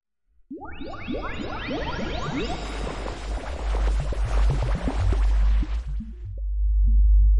空间气泡
描述：使用各种滤波器，延迟，法兰，合唱和混响重新处理VST合成声音。
Tag: 飞船 气泡 经编 外星人 空间 相位